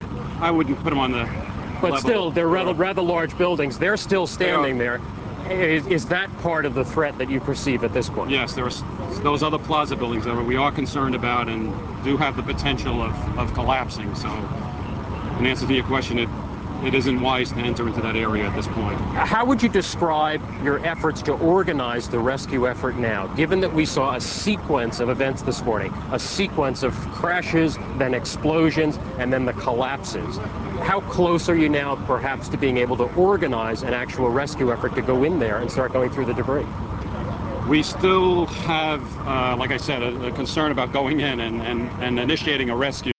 The above QuickTime video was recorded off-the-air from NBC TV station KOAA early afternoon (MDT) on 9/11.